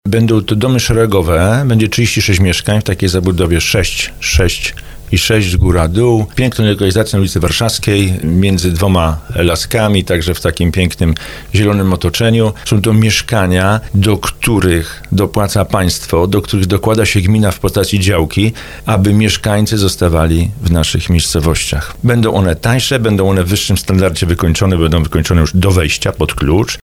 Gmina planuje budowę 36 lokali przy ul. Warszawskiej – mówił o tym w audycji Słowo za Słowo burmistrz Żabna Tomasz Kijowski.